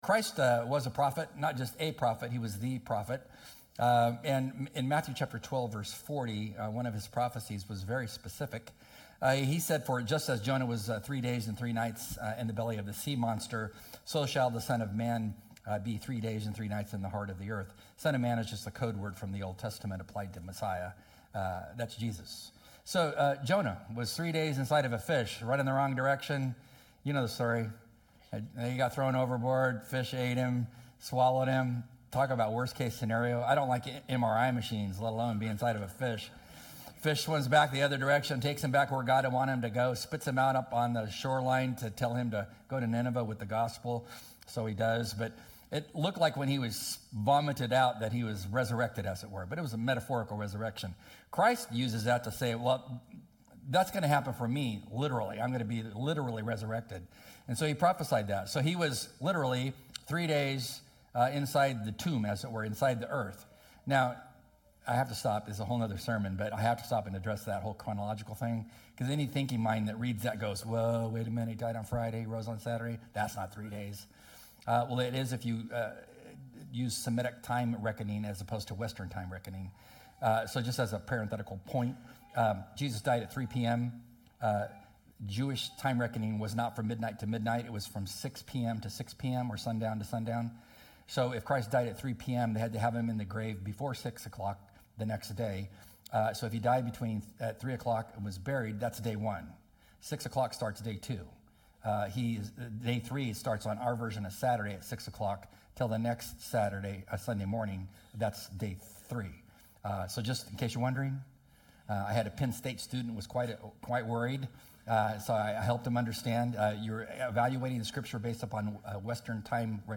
Celebrate Jesus Christ and the hope that He brings! Welcome to the 2025 Easter Sunday service at Burke Community Church.